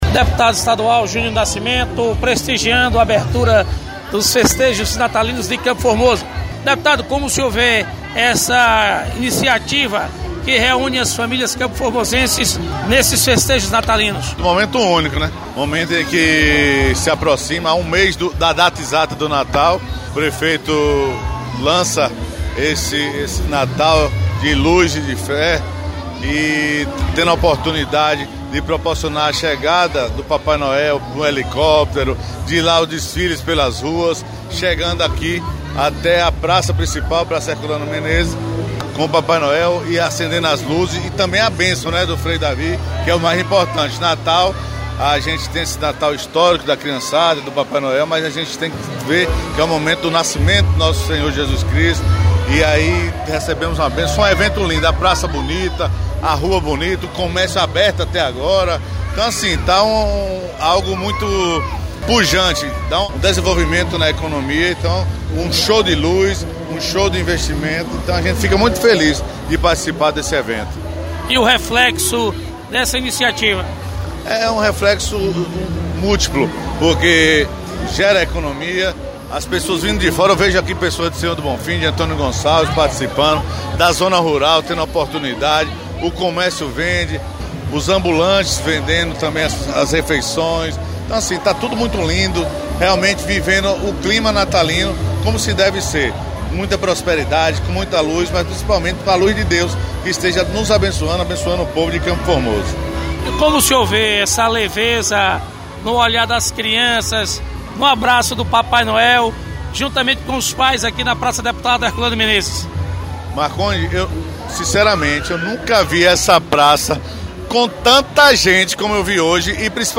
Deputado Júnior Nascimento, presente na chega do papai Noel em CFormoso